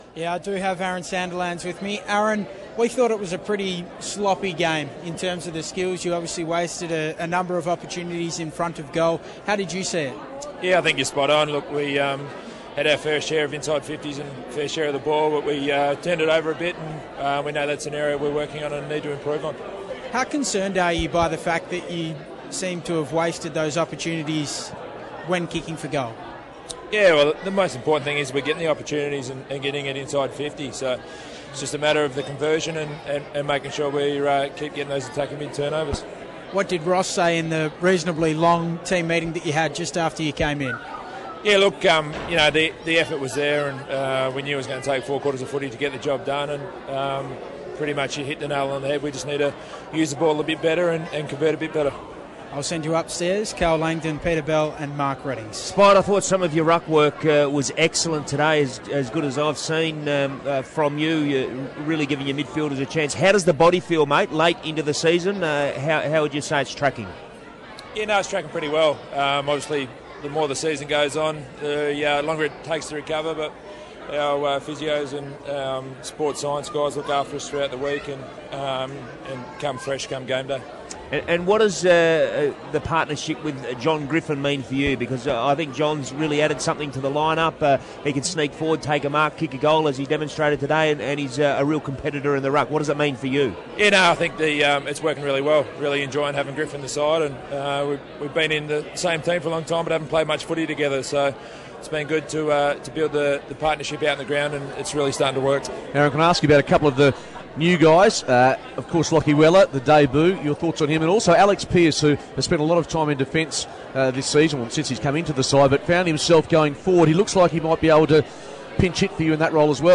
Fremantle Dockers ruckman Aaron Sandilands speaks to the 6PR commentary team after their 21-point win over GWS